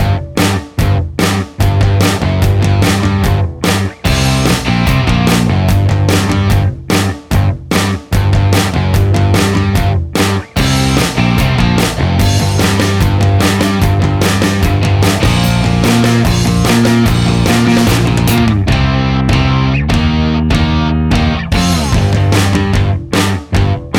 no Backing Vocals Punk 4:01 Buy £1.50